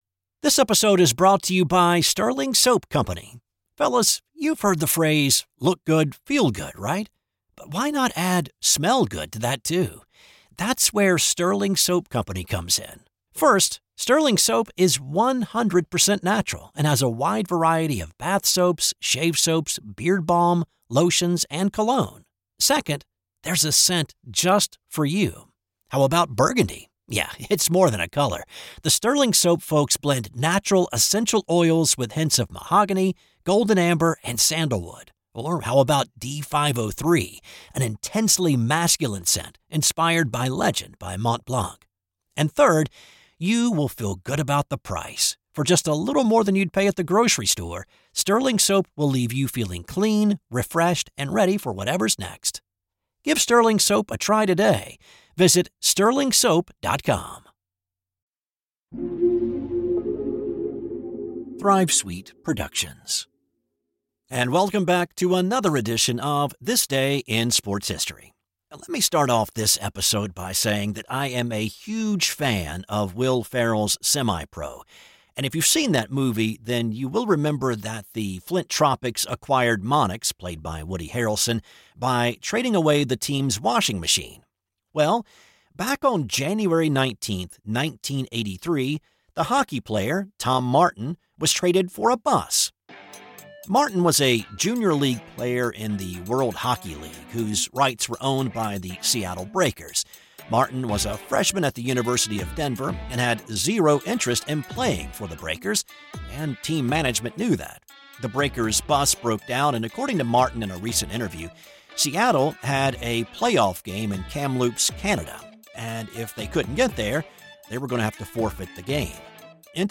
I research, write, voice, and produce each show.